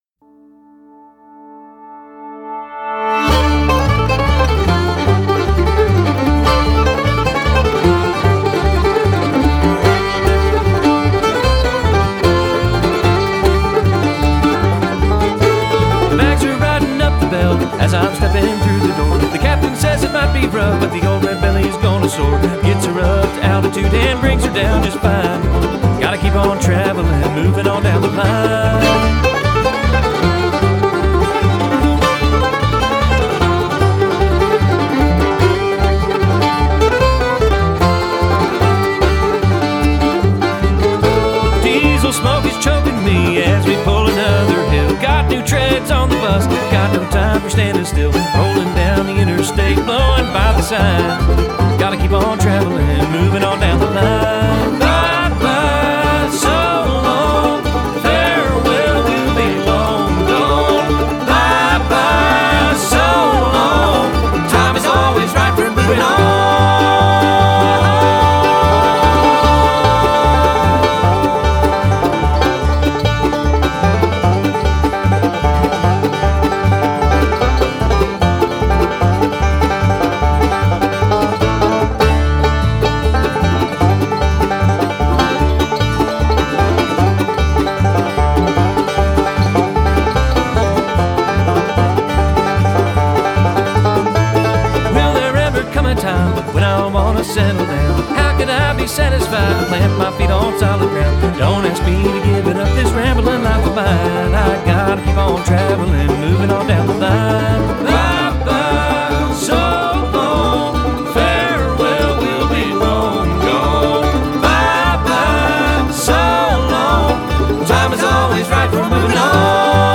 studio tracks